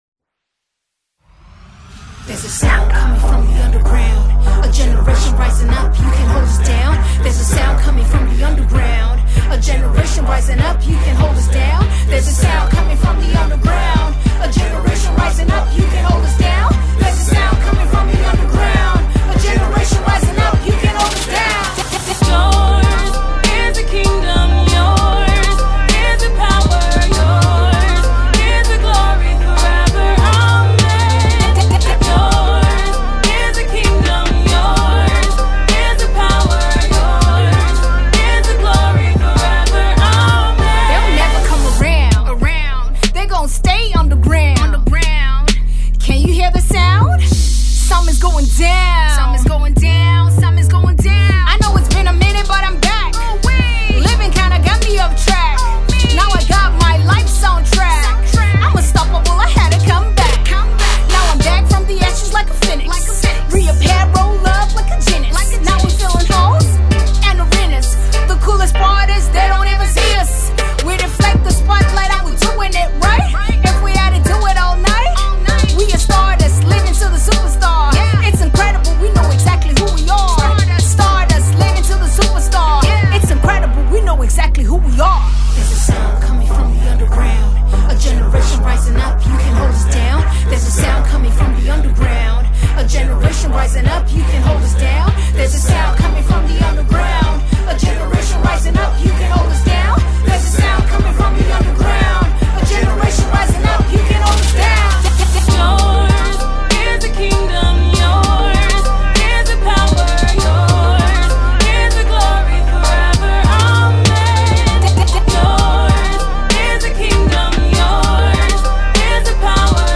The Nigerian foremost female rapper
refined and subtle tune
contemporary hip-hop tune